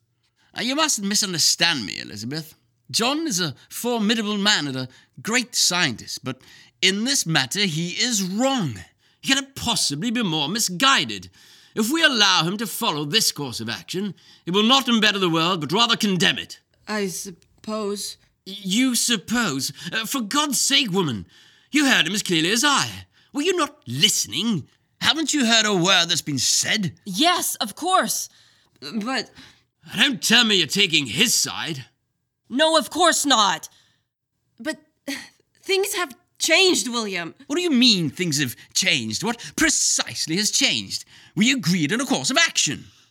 Voiceover sample